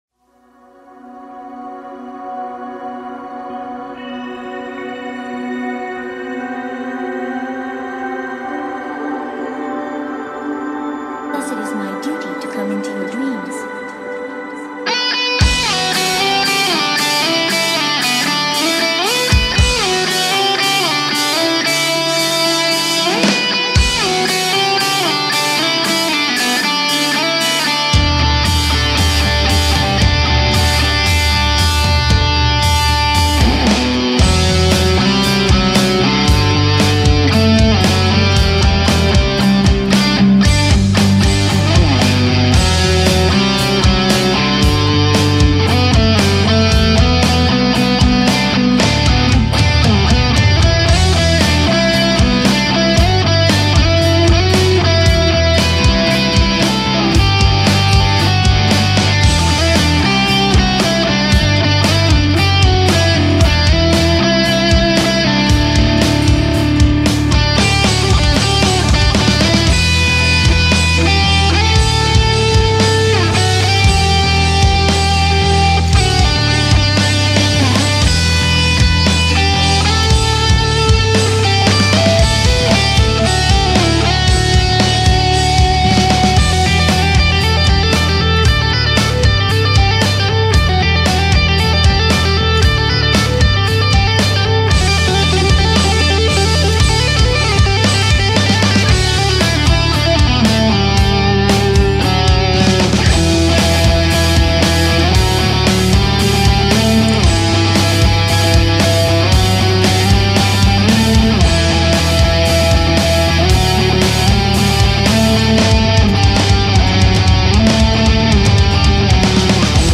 Some guitar riffs for the best princess!